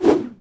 FootSwing2.wav